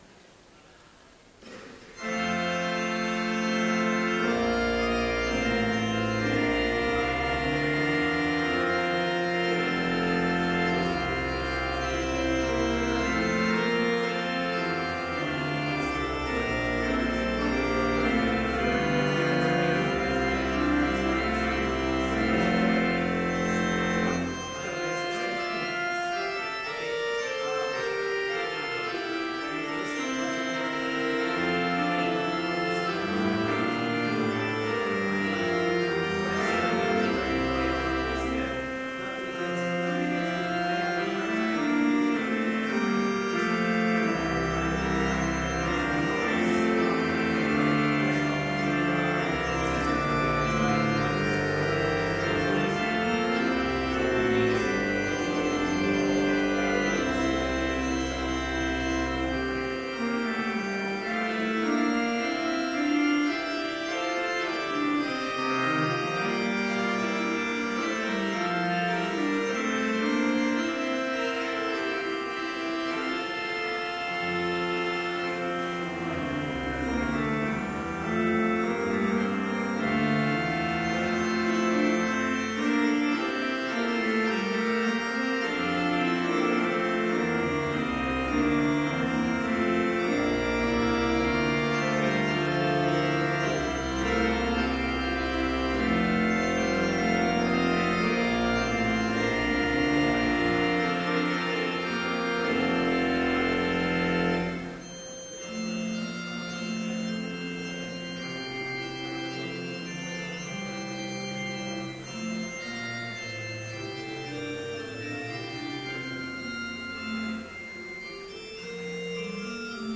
Complete service audio for Chapel - April 29, 2013